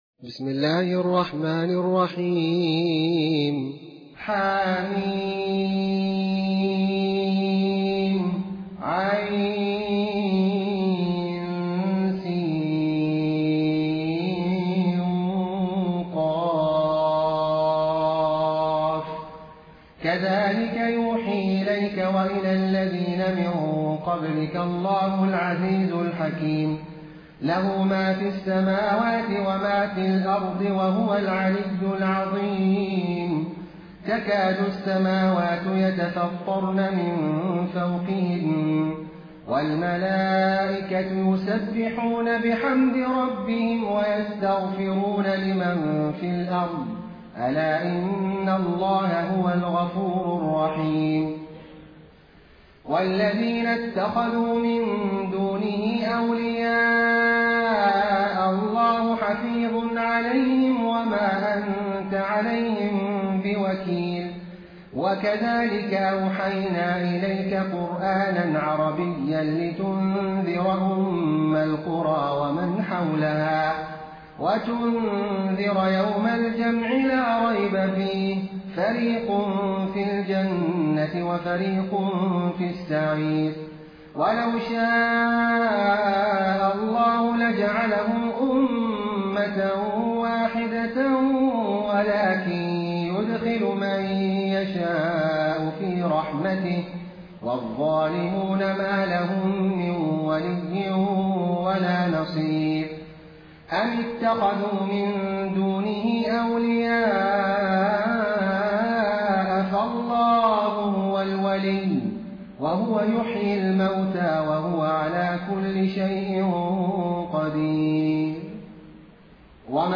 Quran recitations
taraweeh-1433-madina